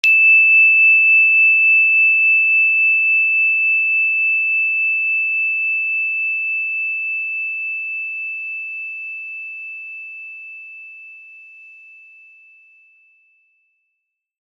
energychime_wood-E6-pp.wav